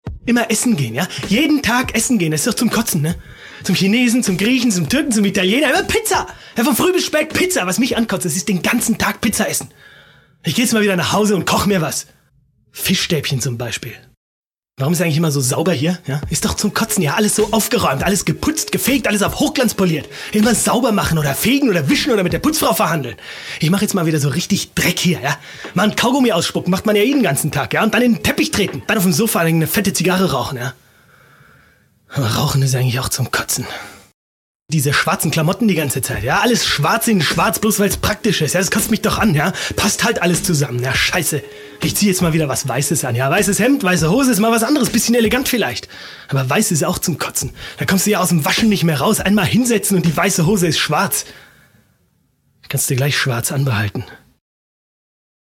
deutscher Sprecher und Schauspieler
schweizerdeutsch
Sprechprobe: Werbung (Muttersprache):